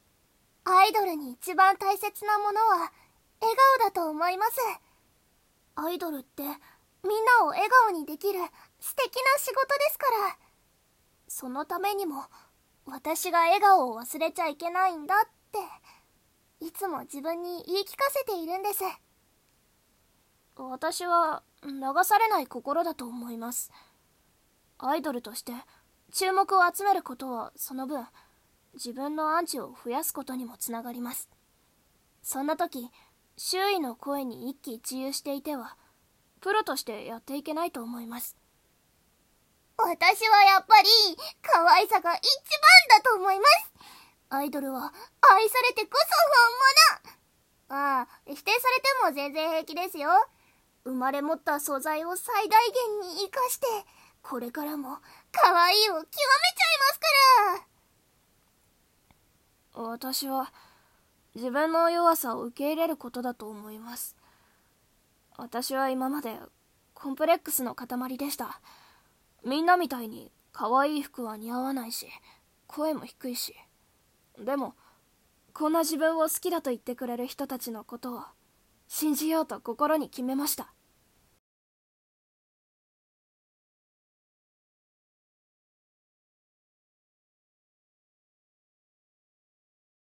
【台本】アイドル演じ分け声面接【1/23の小企画】